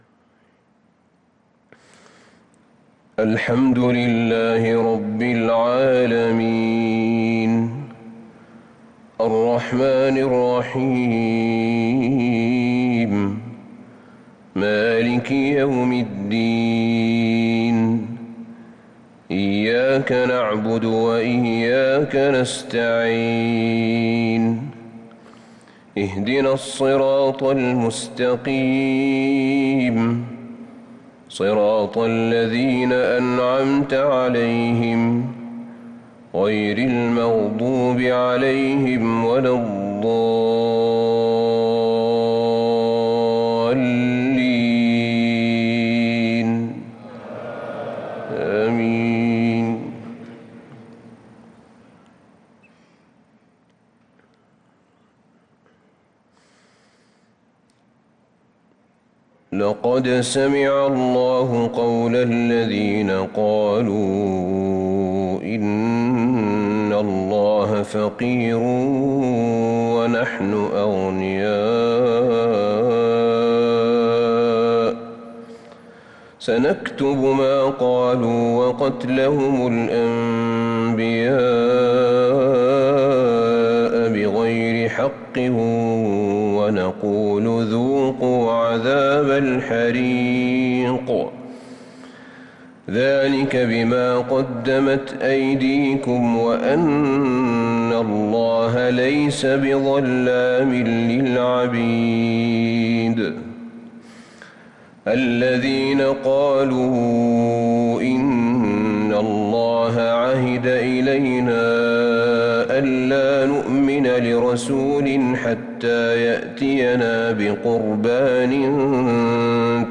صلاة الفجر للقارئ أحمد بن طالب حميد 11 ذو القعدة 1443 هـ